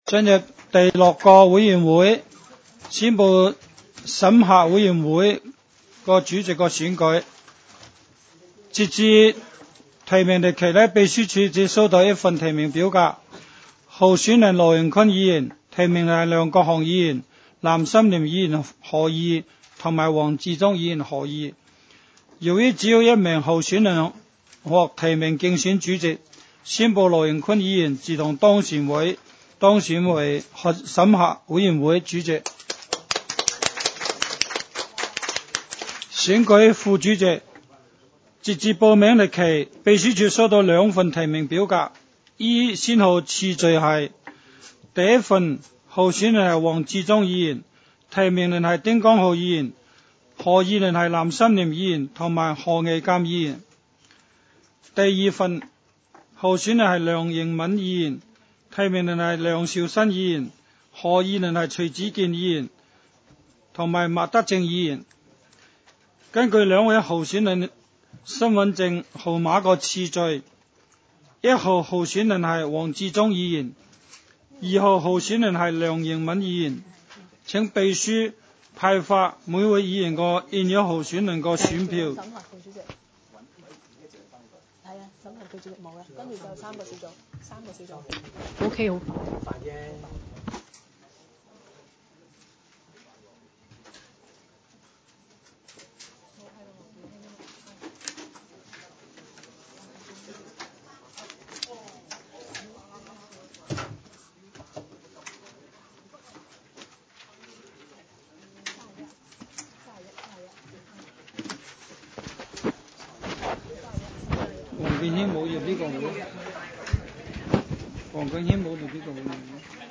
委员会会议的录音记录
审核委员会第一次会议